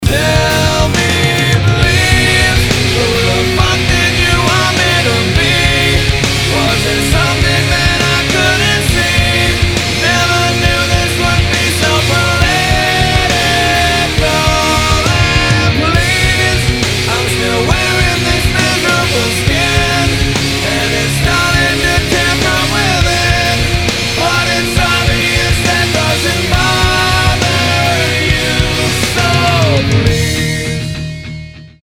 рок рингтоны